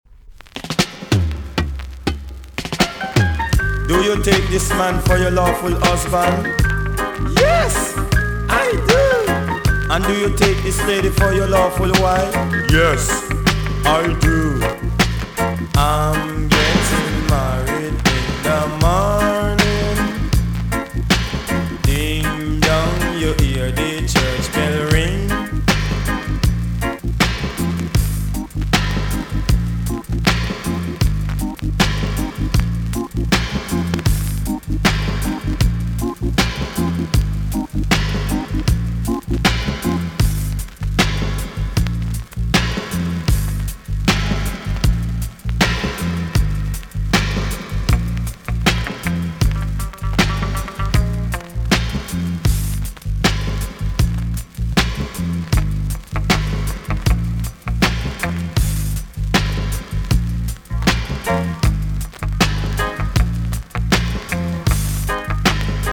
TOP >80'S 90'S DANCEHALL
B.SIDE Version
VG+ 少し軽いチリノイズが入ります。